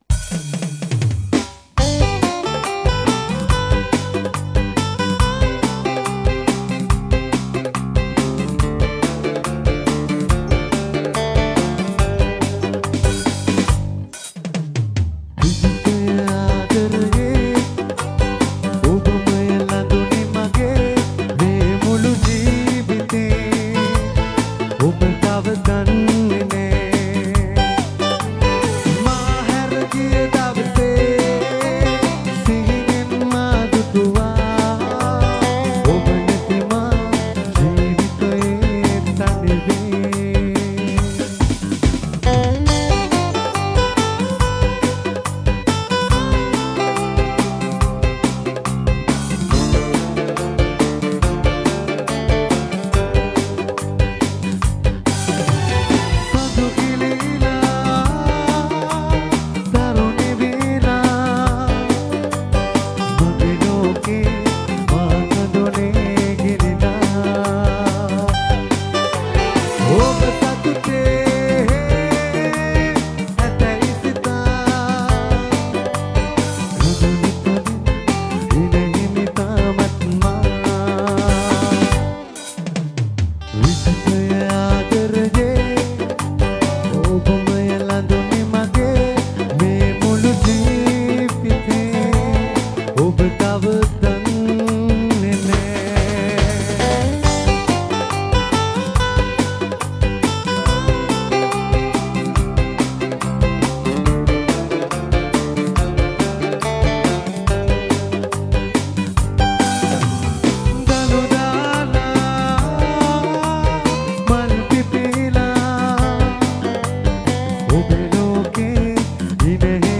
Live in Narangodapaluwa